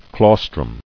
[claus·trum]